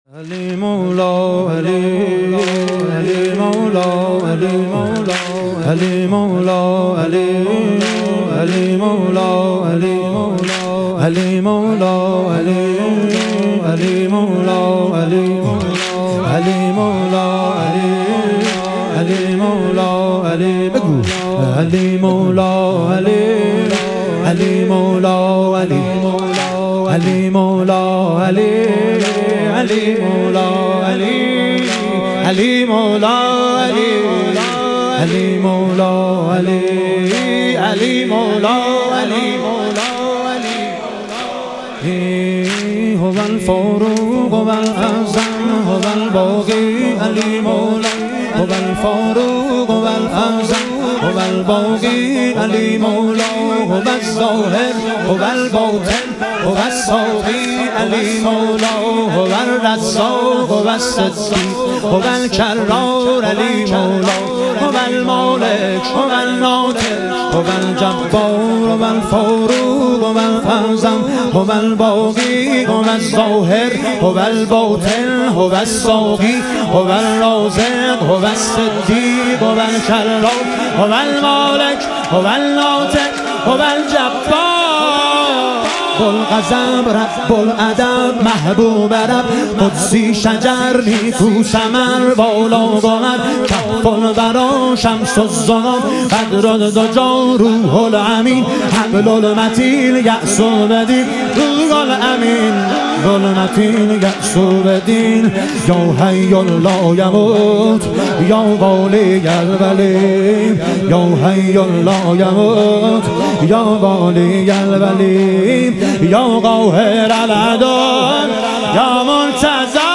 ایام فاطمیه اول - واحد - 11 - 1403